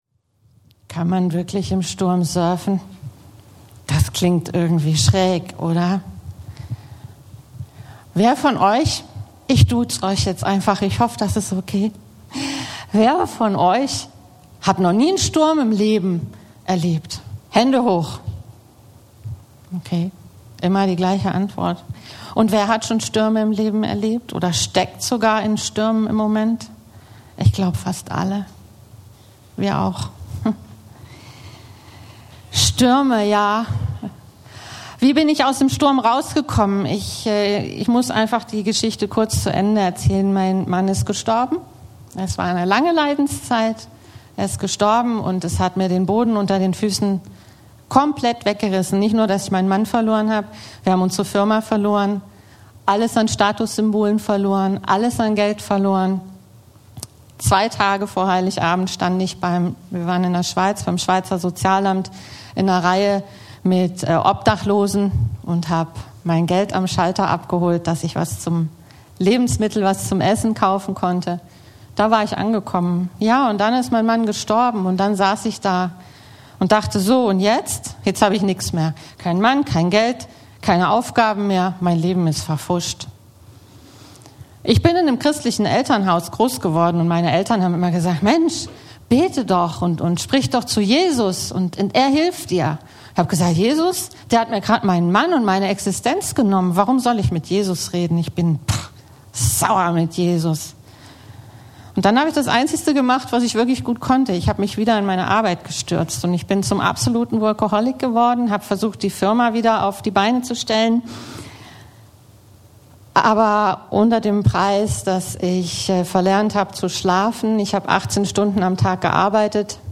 Crossline Gottesdienst Predigt vom Sonntag, den 10. Maerz 2024 ~ Crossline Gottesdienst Steinheim an der Murr Podcast